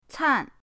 can4.mp3